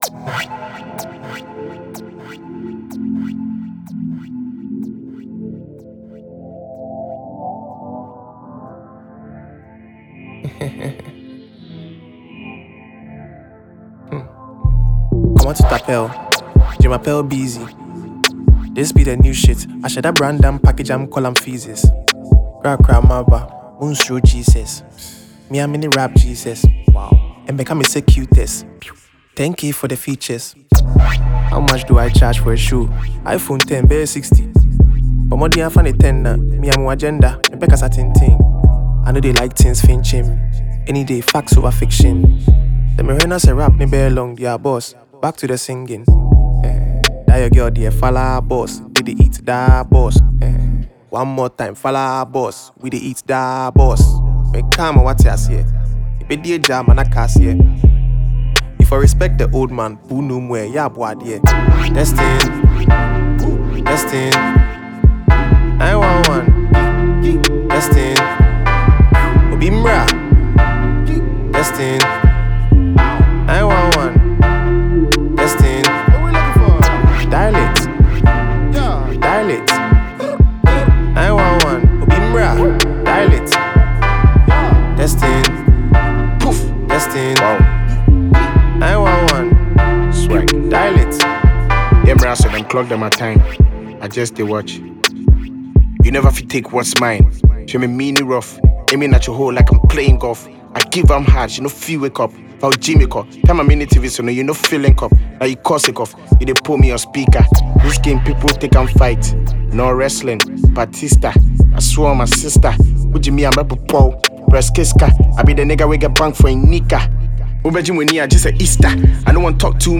Afrobeat/Afropop singer
hammering joint